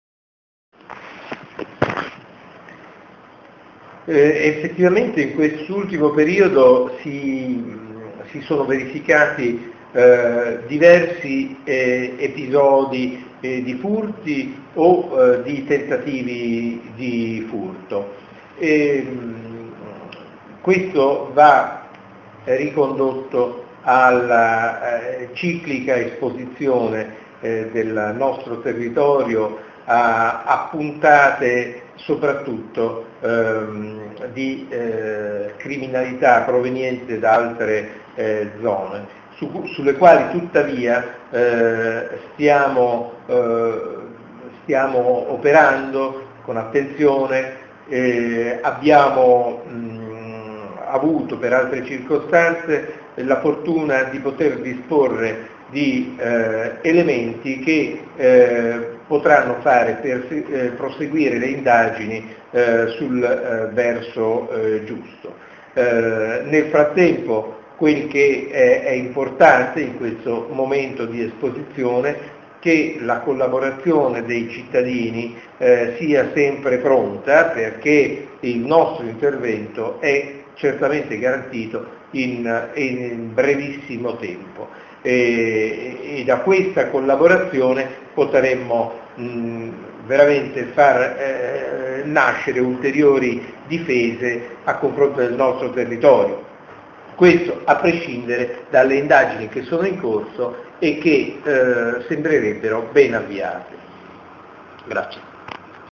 ASCOLTA UNA BREVE DICHIARAZIONE DEL QUESTORE DI CAMPOBASSO GIAN CARLO POZZO